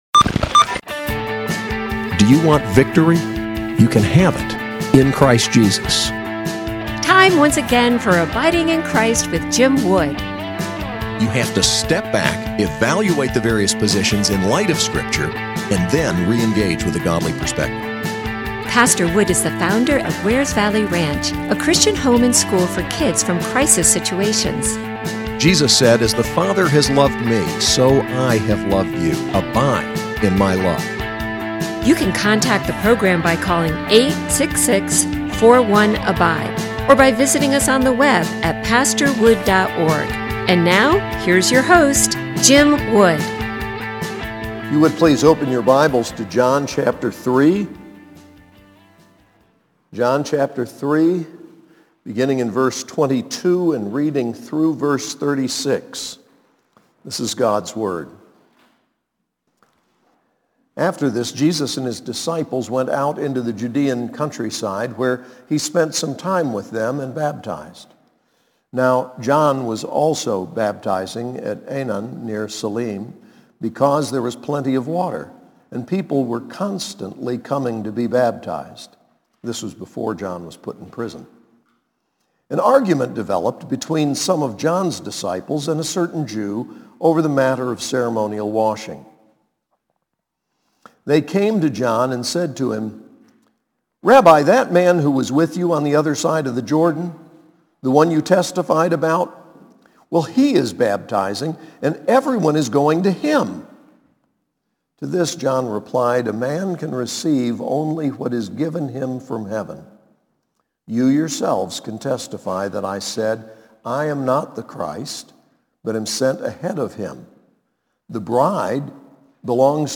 SAS Chapel